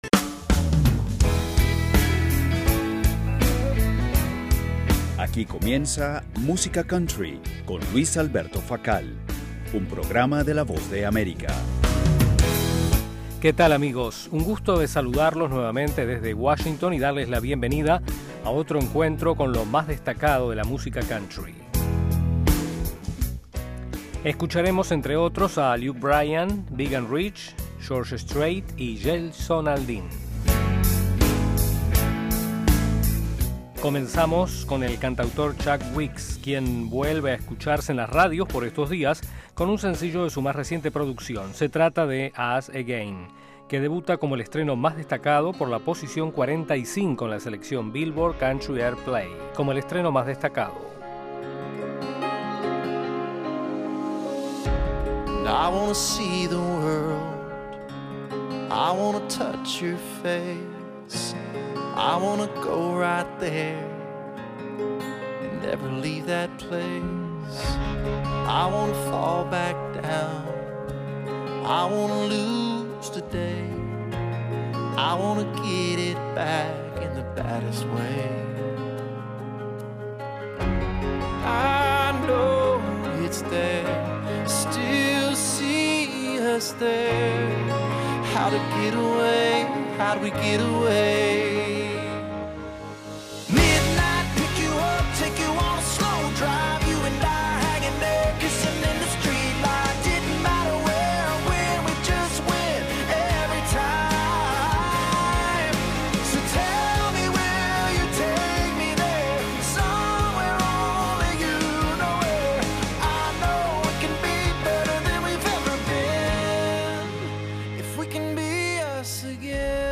el programa musical